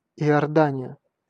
Ääntäminen
Ääntäminen US RP : IPA : /ˈdʒɔːdən/ US : IPA : /ˈdʒɔːrdən/ Lyhenteet ja supistumat (laki) Jor.